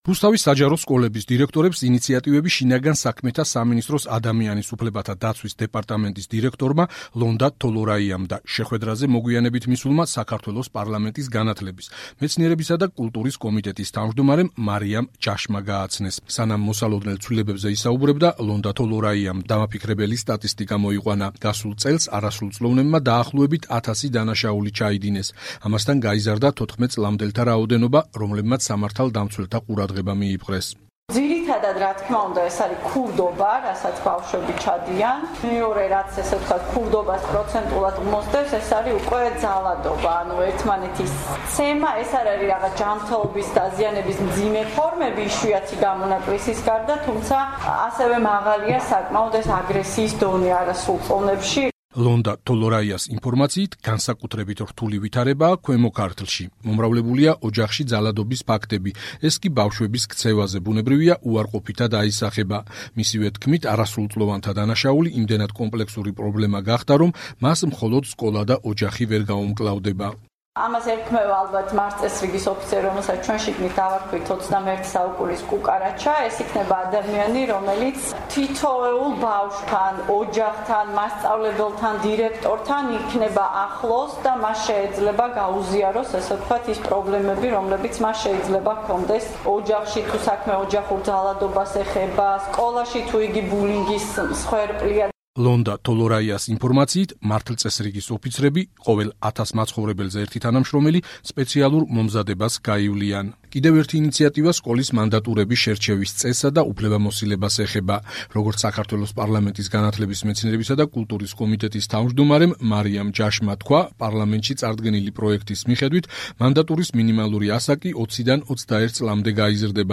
არასრულწლოვანთა შორის დანაშაულის პრევენციისა და სკოლებში უსაფრთხო გარემოს შექმნის მიზნით, ხელისუფლება მთელ რიგ ცვლილებებს გეგმავს. სწორედ ამ საკითხზე გაიმართა 8 მაისს რუსთავის დემოკრატიული ჩართულობის ცენტრში წარმომადგენლობითი შეხვედრა ადგილობრივი სკოლების დირექტორების, პარლამენტარების, შს სამინისტროს, რუსთავისა თუ ქვემო ქართლის ხელმძღვანელების მონაწილეობით.